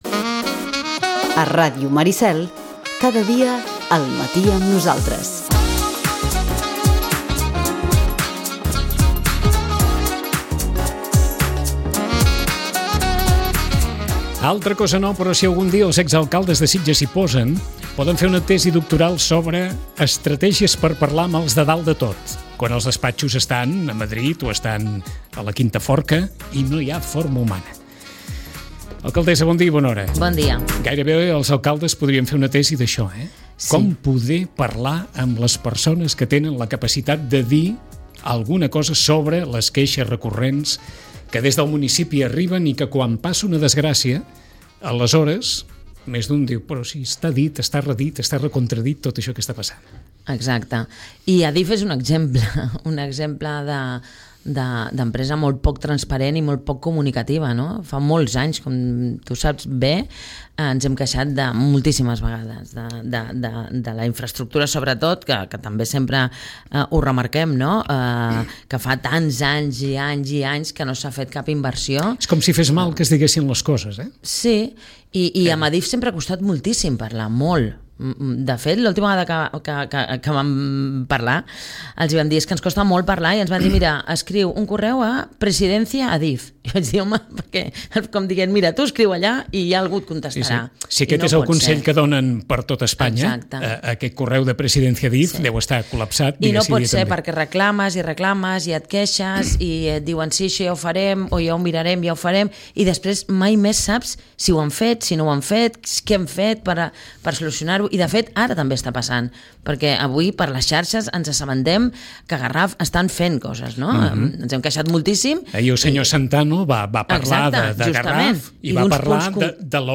Amb l’alcaldessa hem parlat d’això al llarg d’una conversa que ha començat amb rodalies, ha seguit pel Club de Mar i Kansas, per Fitur i la taxa turística, per la moratòria de llicències d’obra en terrenys qualificats com a clau mixta entre l’ús hoteler i d’altres, per comentar també el projecte de Vallcarca, les obres al campanar de la parròquia o la situació de la venda de les instal·lacions del Club Natació.